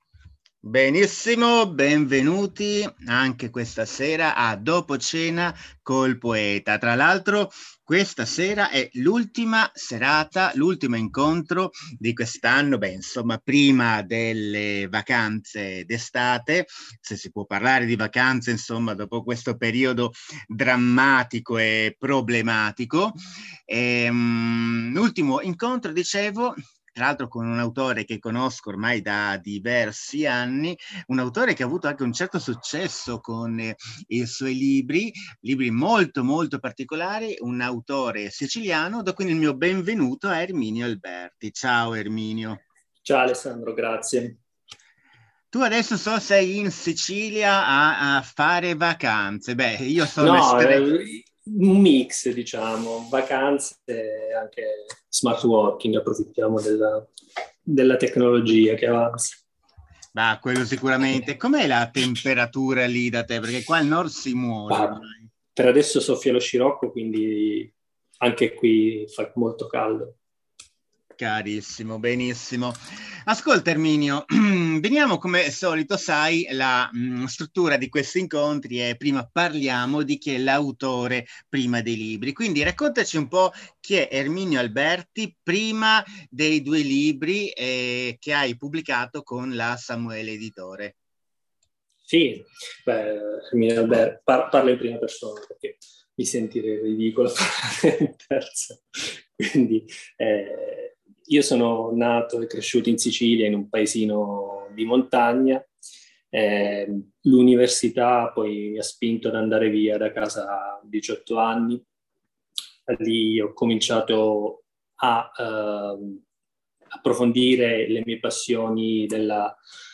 Piccole dirette in solo streaming audio coi poeti della Samuele Editore